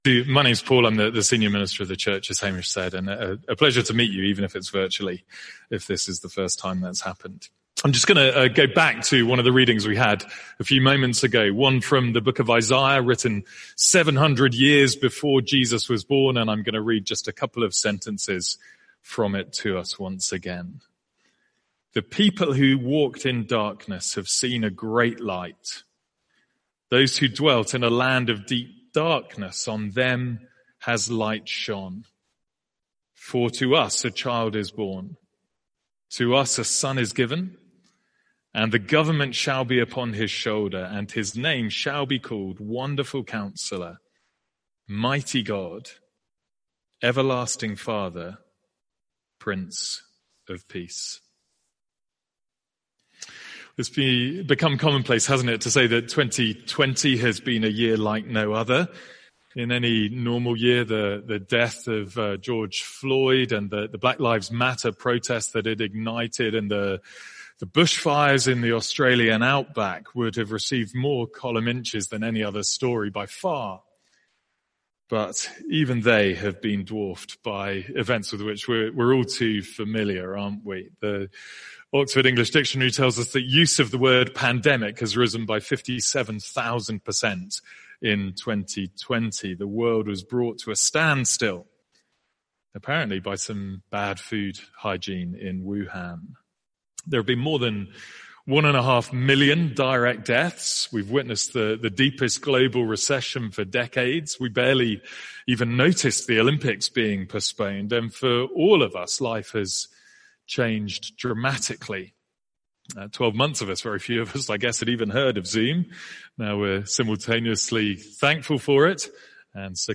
Carol Service – Isaiah 9:1-7
From our Carol Service.